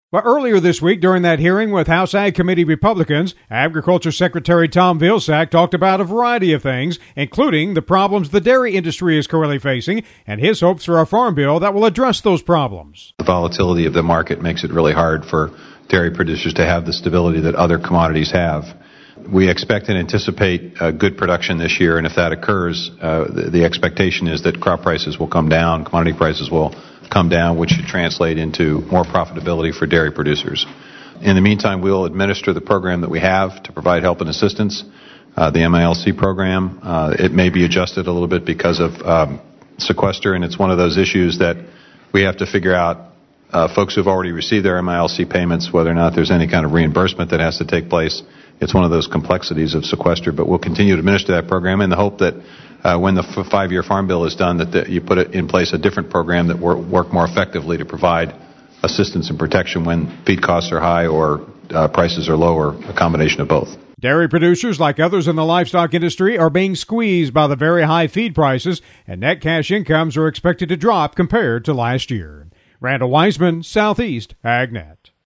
Agriculture Secretary Tom Vilsack, during a hearing with House Ag Committee Republicans this week, talked about the problems folks in the dairy industry are currently facing.